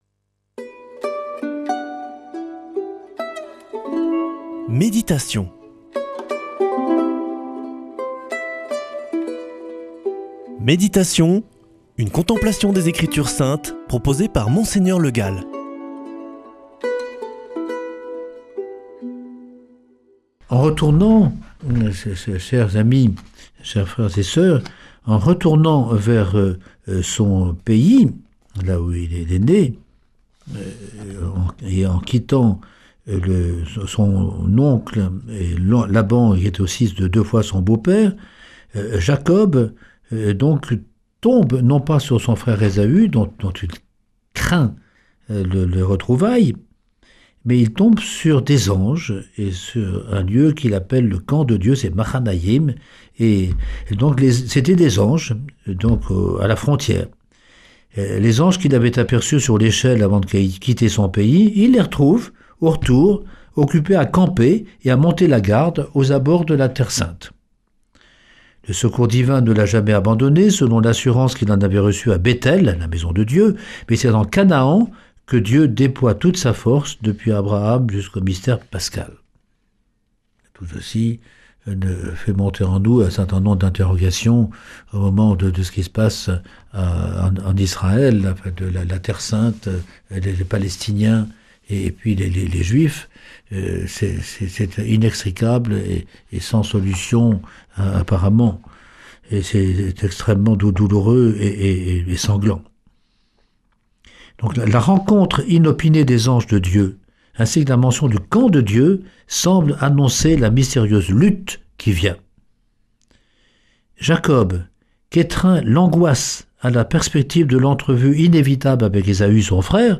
[ Rediffusion ] La lutte avec Dieu
Méditation avec Mgr Le Gall